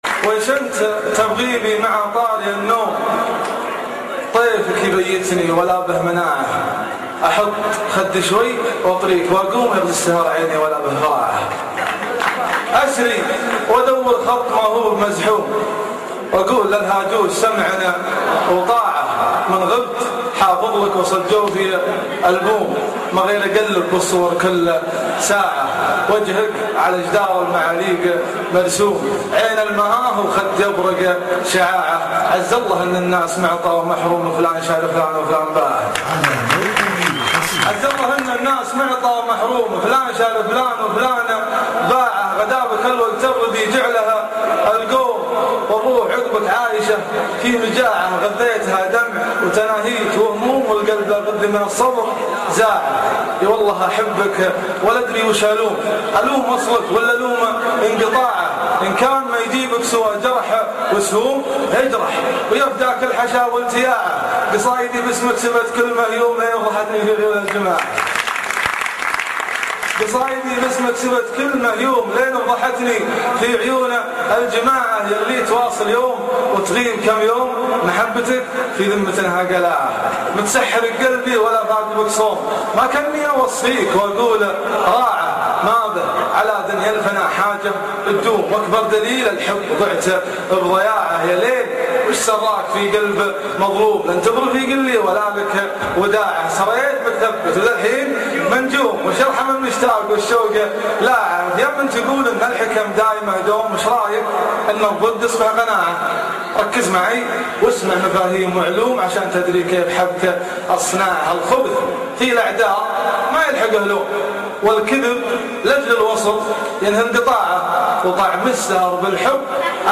وش انت تبغي بي مع طاري النوم ( اصبوحة جامعة الكويت